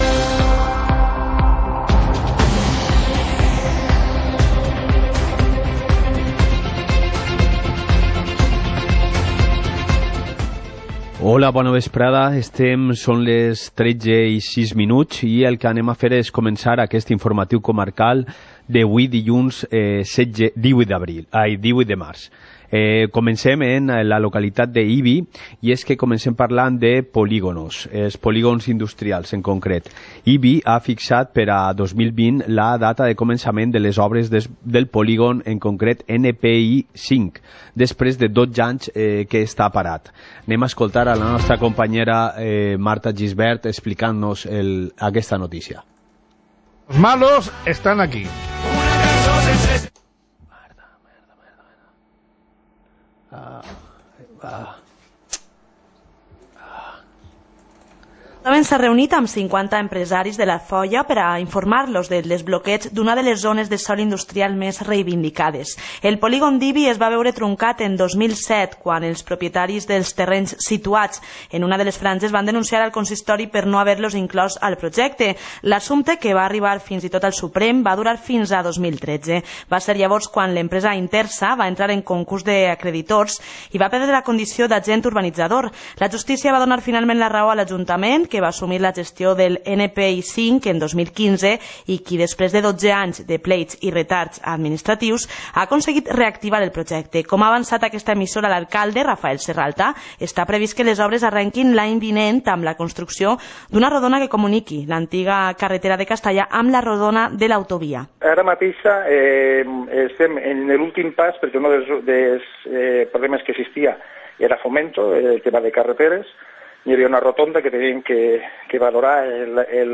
Informativo comarcal - lunes, 18 de marzo de 2019